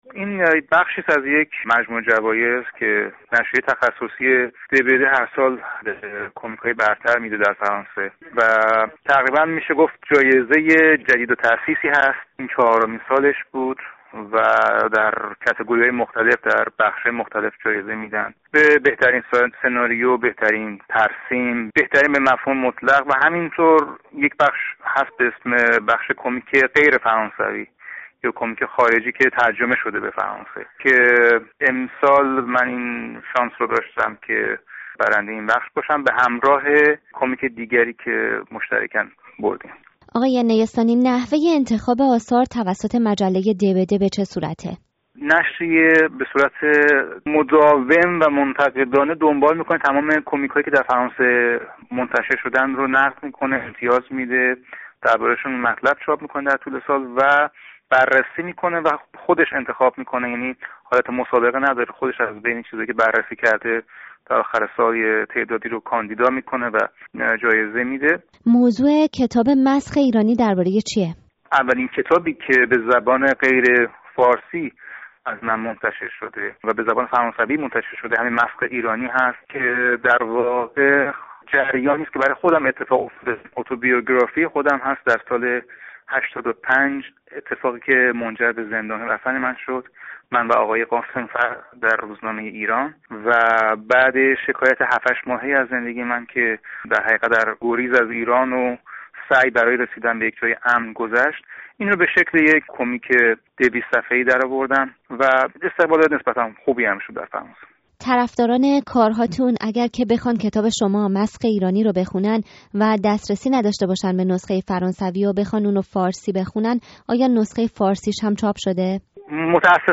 مانا نيستانی٬ کاريکاتوريست ايرانی مقيم پاريس جايزه بهترين کميک خارجی مجله «د.ب.د» را برای کتاب «مسخ ايرانی» برد. اين کاريکاتوريست که تاکنون جوايز زيادی دريافت کرده٬ می‌گويد اين کتاب در حقيقت شرح حال او پس از خروجش از ايران است. راديو فردا به بهانه جايزه بهترين کميک خارجی مجله «د.ب.د» با مانا نيستانی گفت‌وگو کرده است.